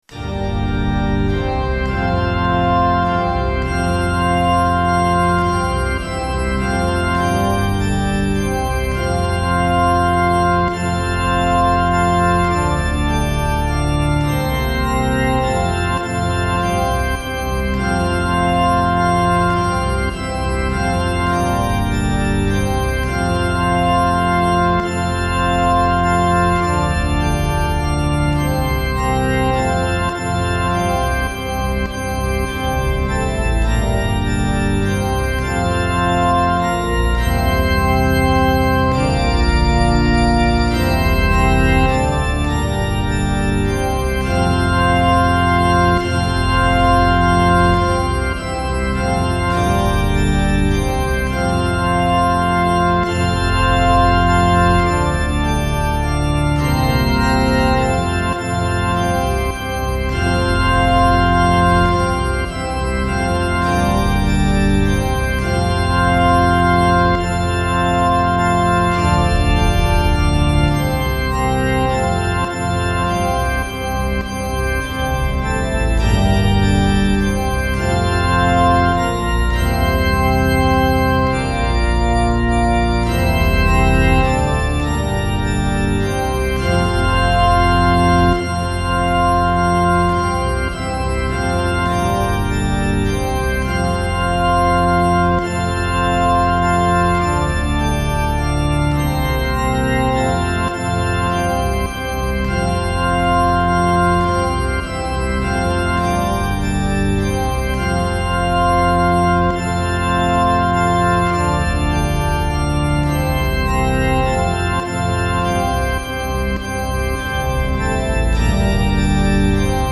My backing is all fake organ.